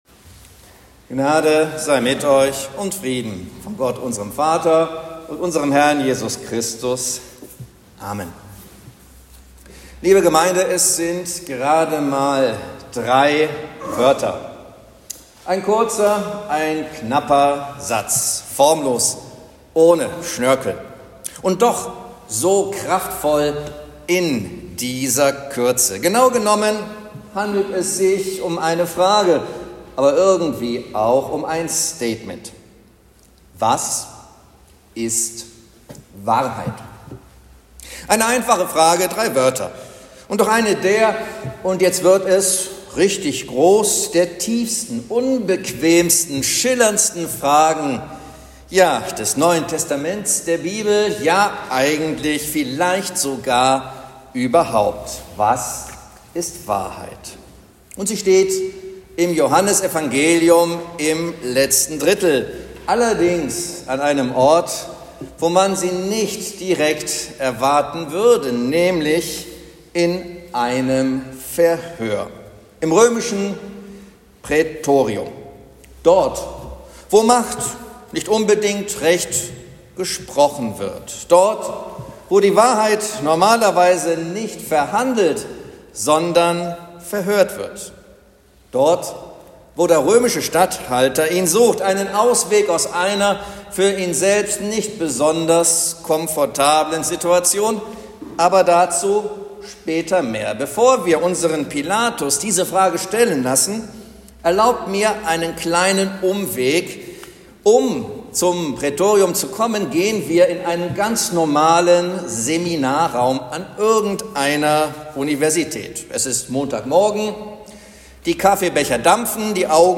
Predigt zu Judika